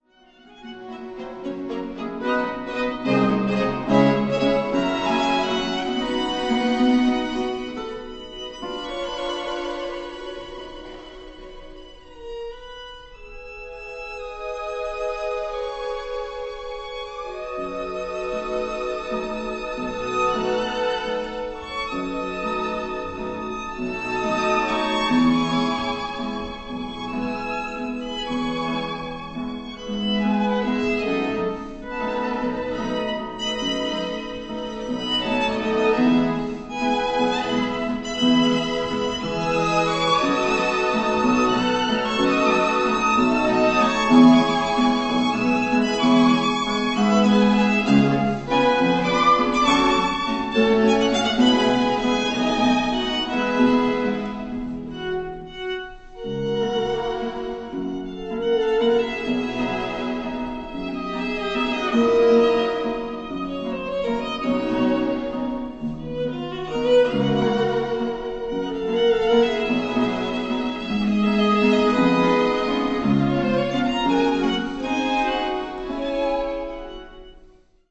** Quartett mit Knopfharmonika
Aufgenommen live am 13.5.2007,
Walzer ** 6'21